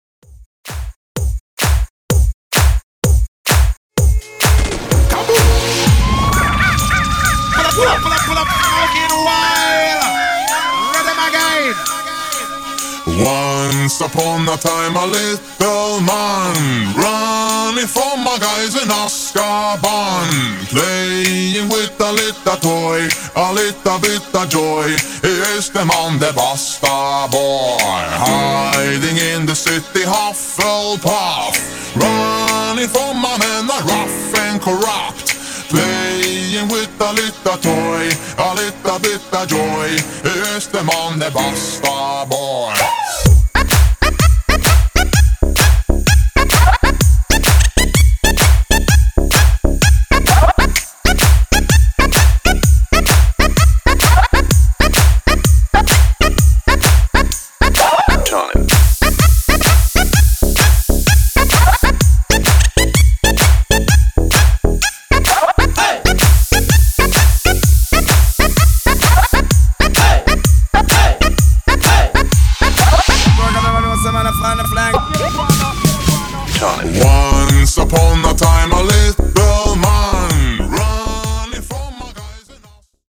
Genres: 70's , RE-DRUM Version: Clean BPM: 120 Time